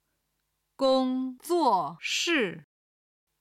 今日の振り返り！中国語音声